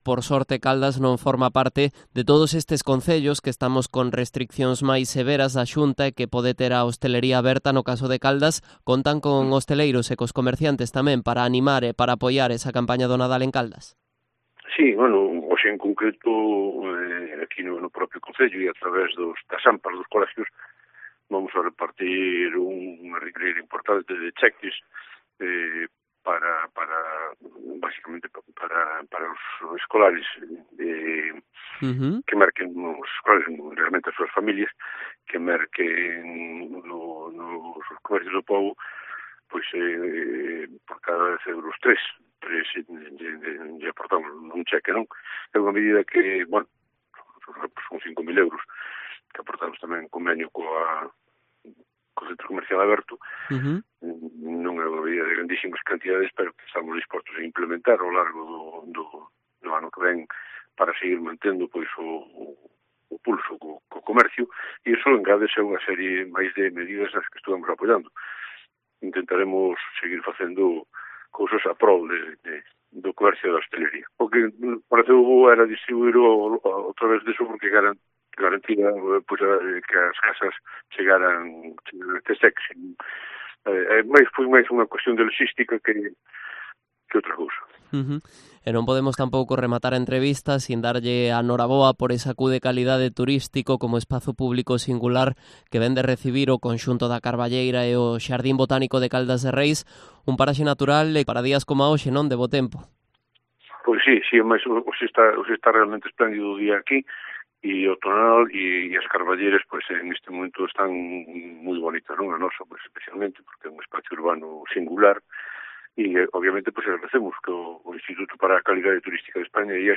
Entrevista a Juan Manuel Rey, alcalde de Caldas de Reis